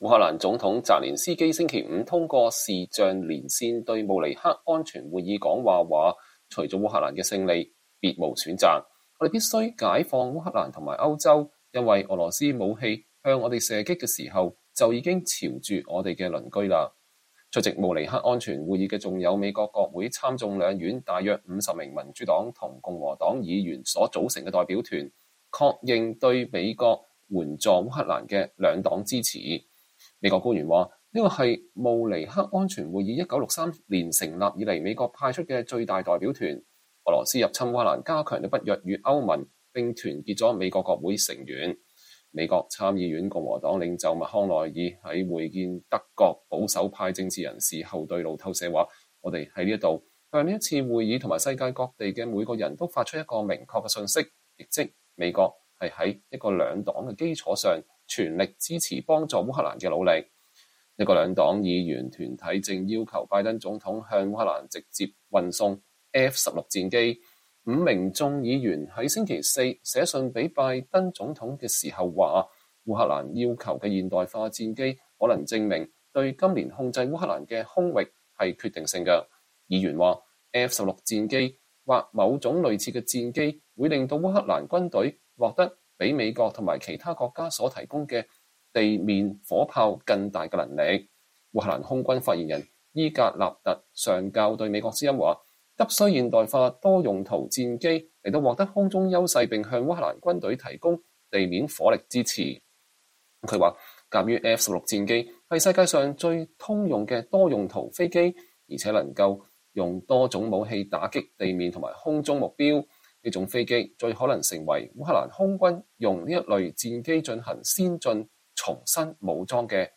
烏克蘭總統澤連斯基星期五通過視頻連線對慕尼黑安全會議講話說，“除了烏克蘭的勝利，別無選擇，”“我們必須解放烏克蘭和歐洲。